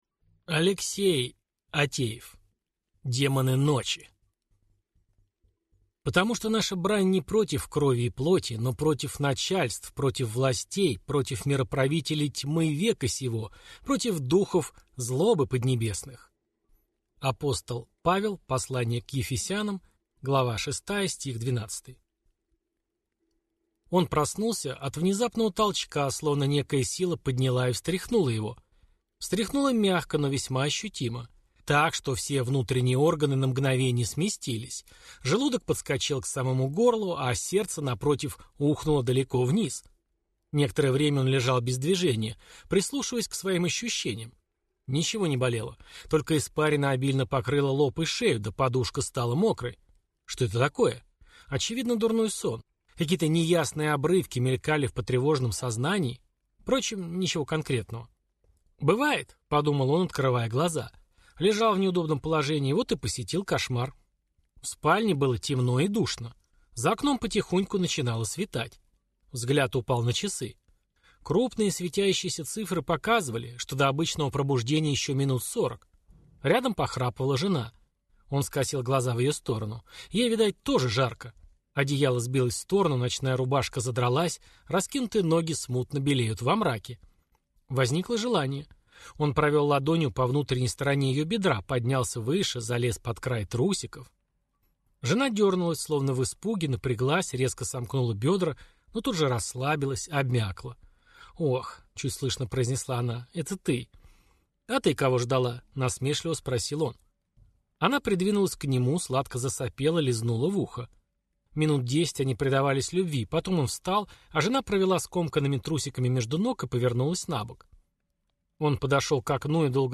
Аудиокнига Демоны ночи | Библиотека аудиокниг